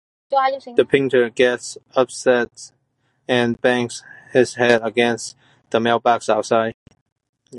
/bæŋ(ɡ)z/